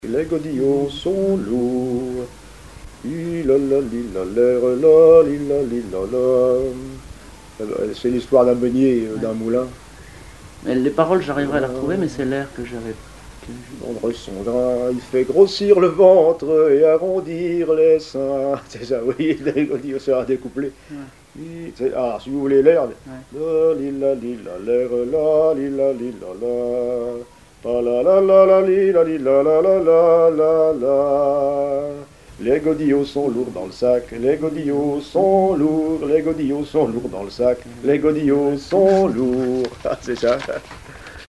Genre laisse
Chansons traditionnelles et témoignages
Pièce musicale inédite